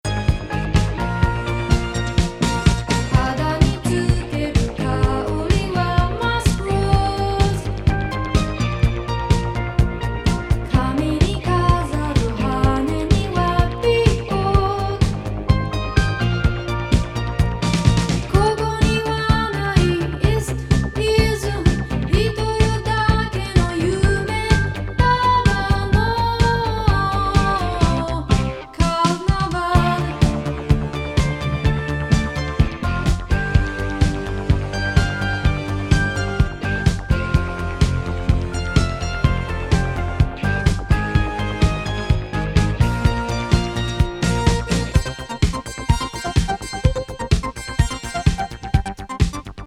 スペーシー・ブレイクもカッコイイ